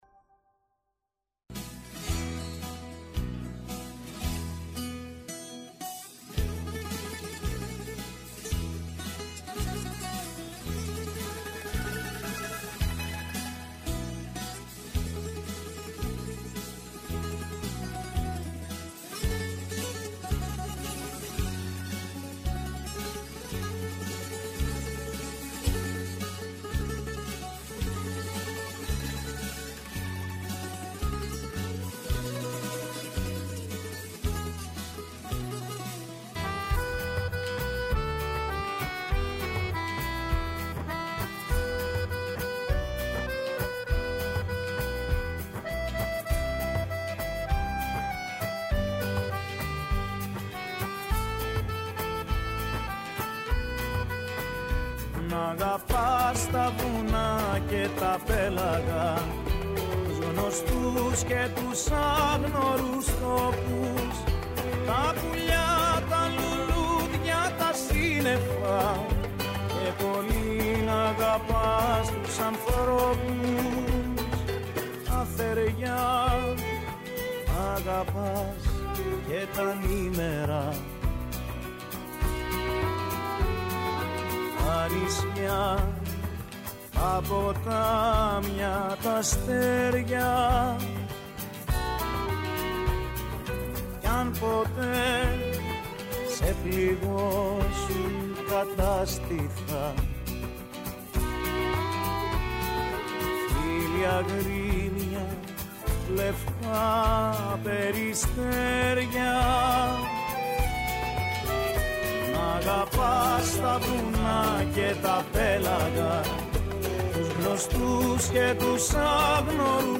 Η εκπομπή ΩΡΑ ΕΛΛΑΔΑΣ μεταδίδεται στην “Φωνή της Ελλάδας”, το παγκόσμιο ραδιόφωνο της ΕΡΤ, κάθε Δευτέρα και Τρίτη στις 9 το πρωί…
Συνεντεύξεις